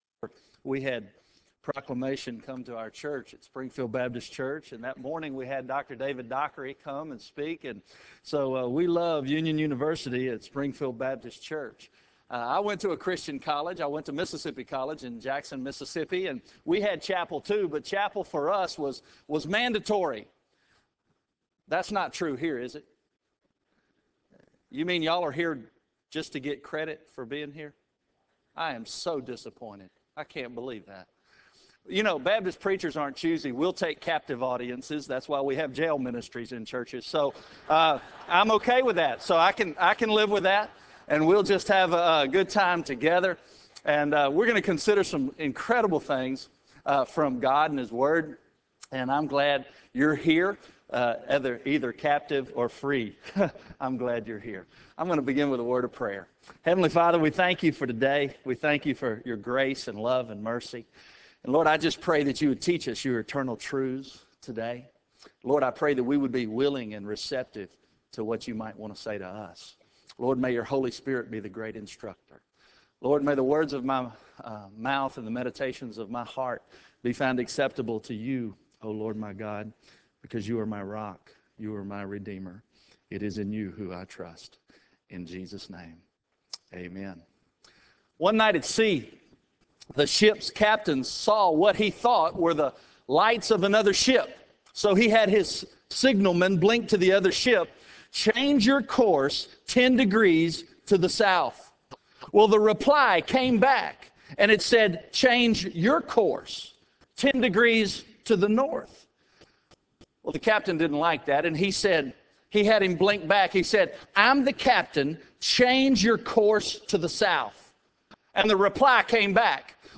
Chapel
Address: "The Basins of Life" from Matthew 27:20-24 and John 13:1-5 Recording Date: Mar 29, 2006, 10:00 a.m. Length: 33:53 Format(s): WindowsMedia Audio ; RealAudio ; MP3 ;